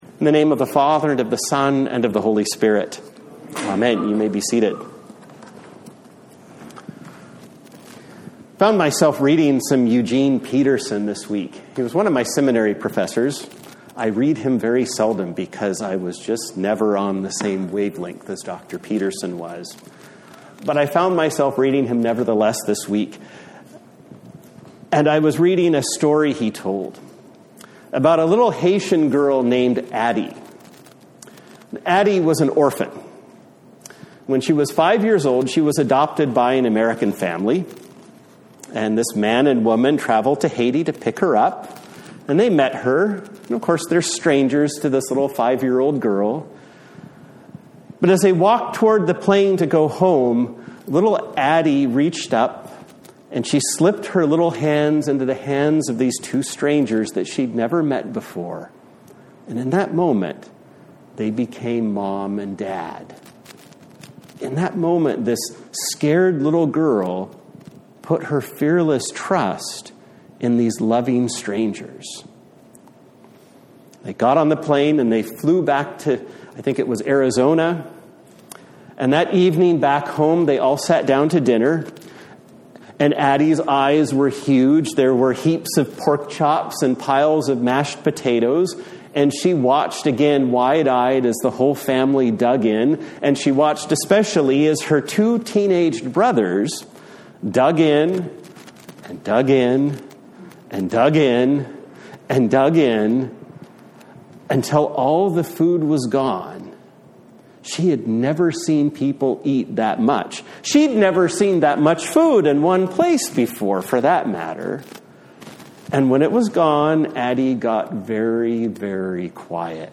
Passage: Ephesians 3:14-21 Service Type: Sunday Morning